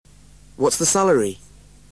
PITCH IN BRITISH ENGLISH
After listening to each audio file, repeat it aloud trying to imitate the intonation:
FRIEND TO FRIEND